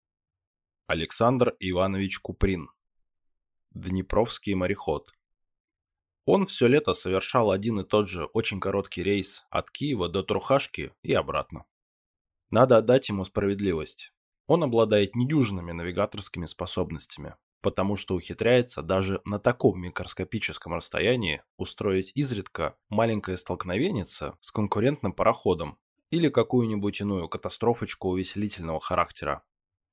Аудиокнига Днепровский мореход | Библиотека аудиокниг